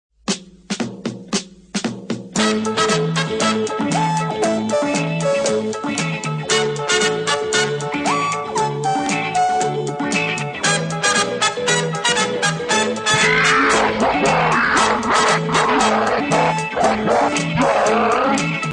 забавные
смешные
рычание